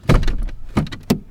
GearShifting3.WAV